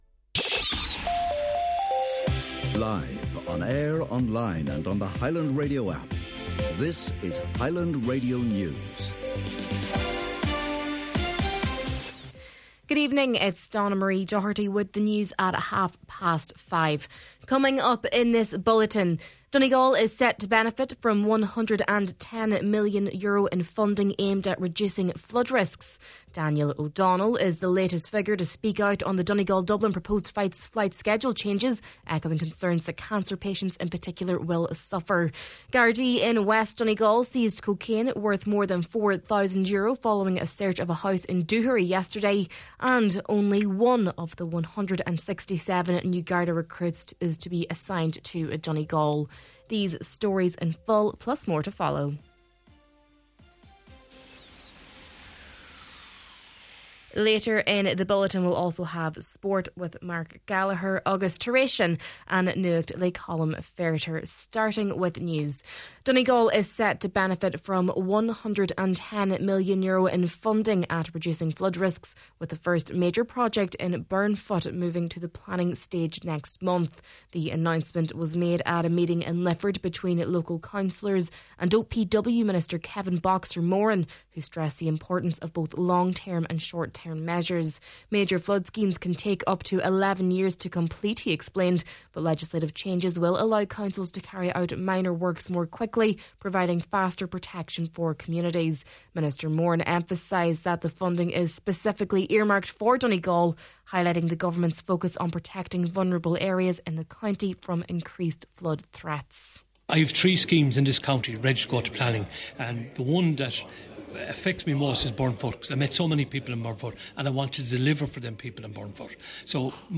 News, Sport, An Nuacht and Obituary Notices – Friday, February 6th